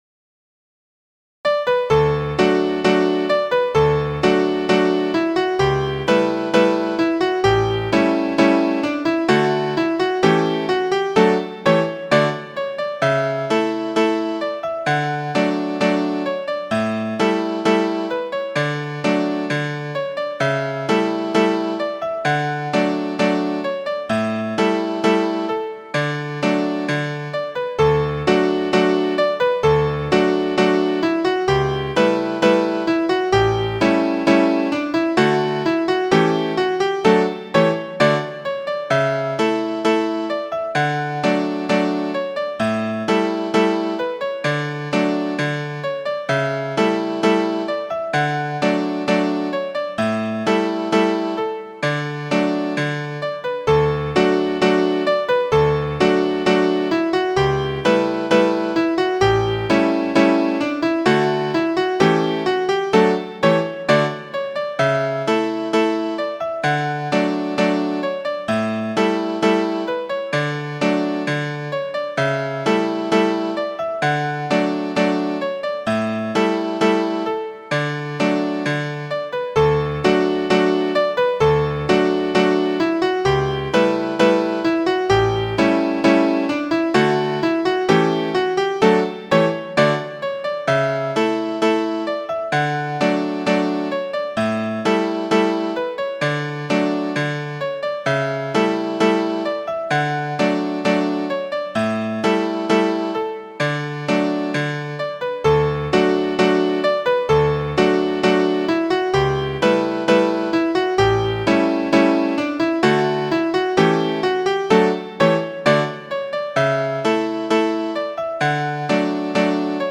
A repeating sixteenth rhythm pattern.
• Key: D Major
• Time: 2/4